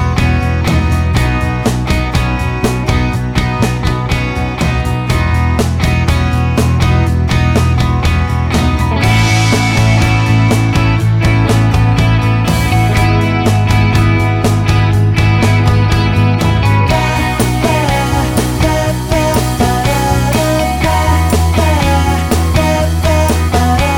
no Backing Vocals Indie / Alternative 3:27 Buy £1.50